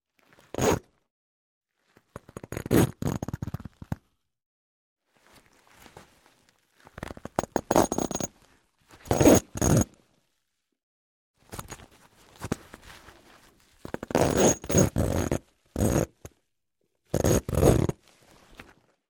Звуки сумки, ранца
Звук расстегивания молнии на сумке